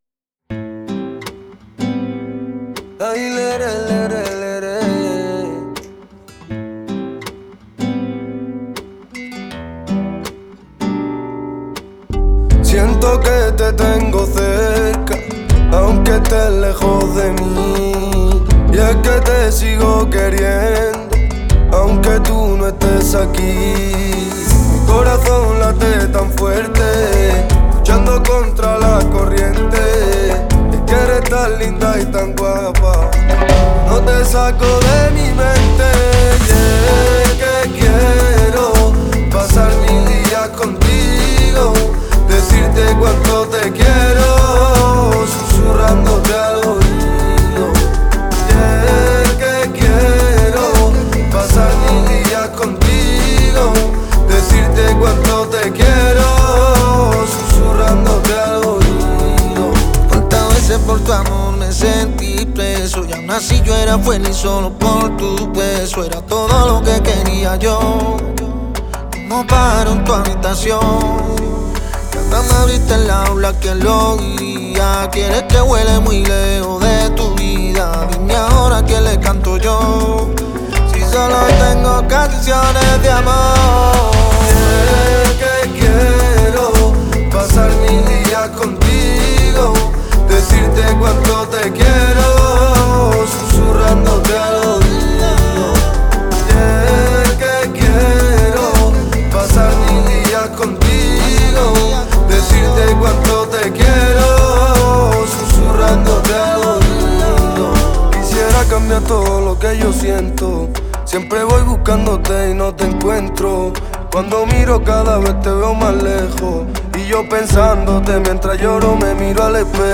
это зажигательная песня в жанре латинского рэпа и реггетона